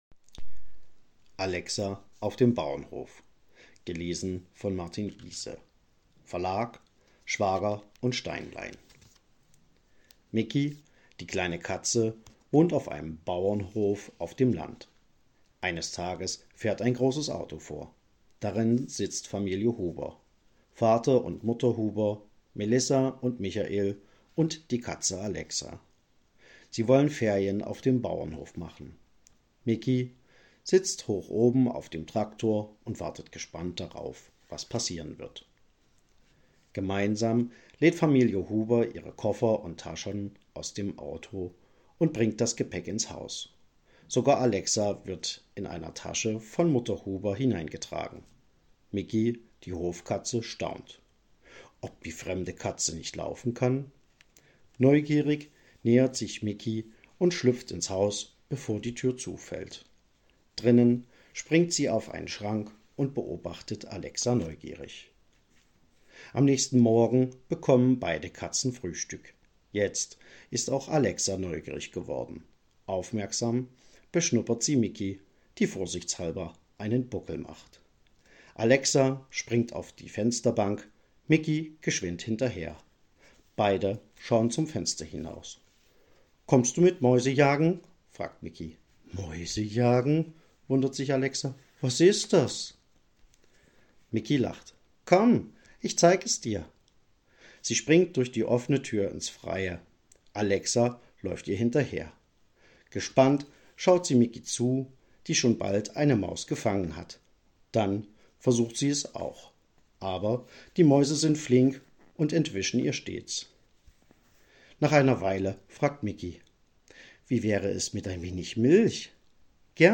Kinderbücher vorlesen